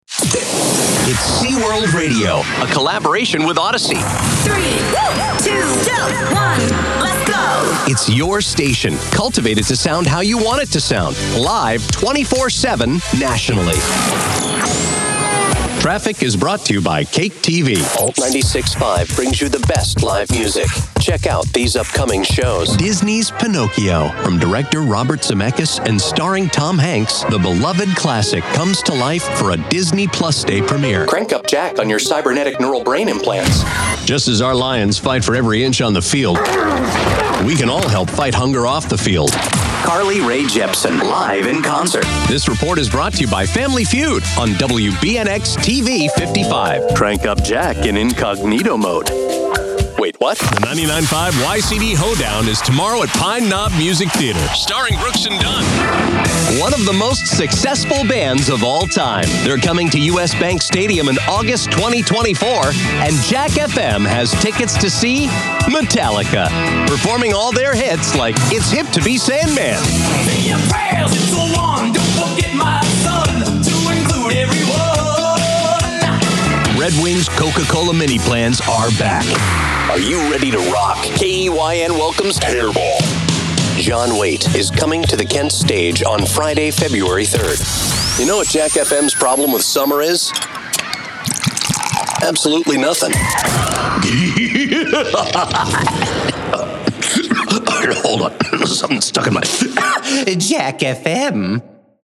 Male
Adult (30-50), Older Sound (50+)
For promo work, the movie-trailer announcer comes out.
Promo / Announcers
Words that describe my voice are conversational, deep, gravelly.